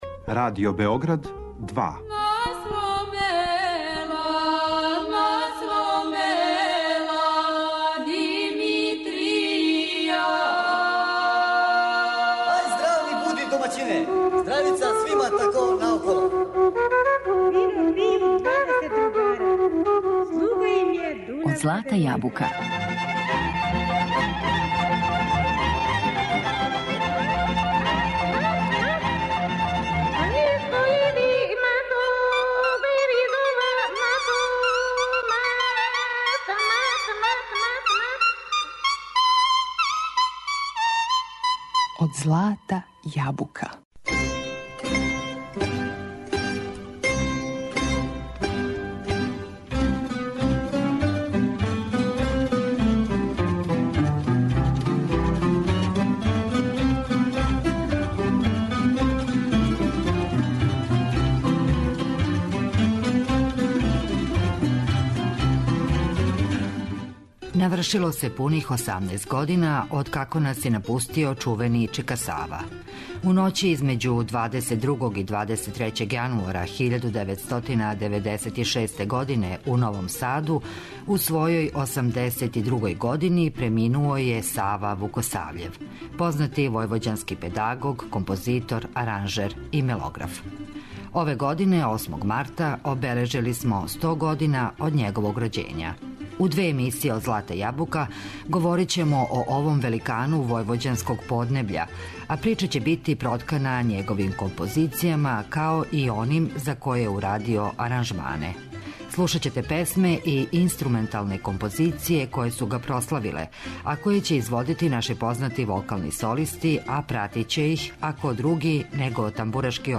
Прича ће бити проткана његовим композицијама у извођењу познатих вокалних солиста, уз пратњу Великог тамбурашког оркестра Радио Новог Сада. Cлушаћемо његове познате песме и инструментална дела која су га прославила.